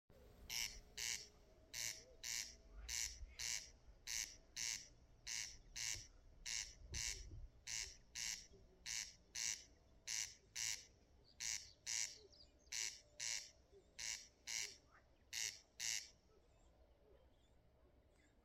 Putni -> Raļļi ->
Grieze, Crex crex
StatussDzirdēta balss, saucieni